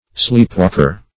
\Sleep"walk`er\